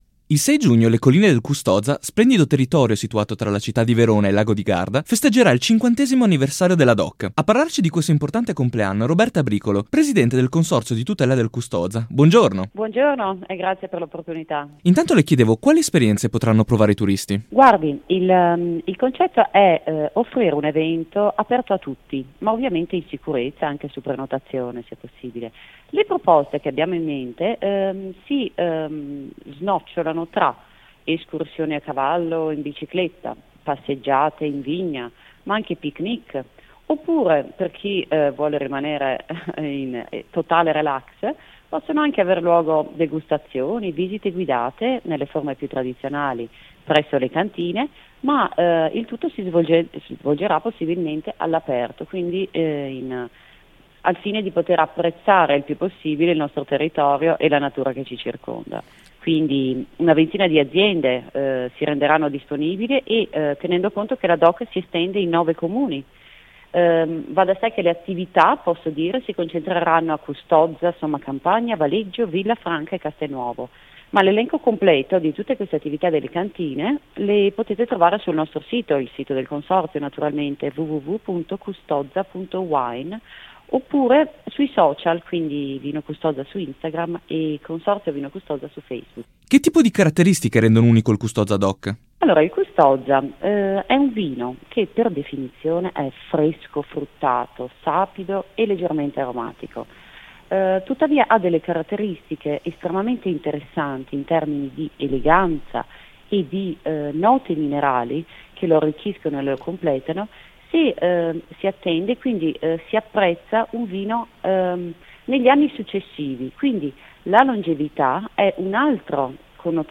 Custoza-intervista.mp3